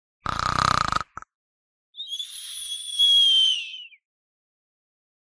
リラックスした雰囲気を演出したり、睡眠状態を表現するのに最適なサウンドです。
深い眠りを誘う、リアルないびき音 着信音
まるで本物のようなリアルないびき音は、リラックスした雰囲気を演出したり、睡眠状態を表現するのに最適なサウンド素材です。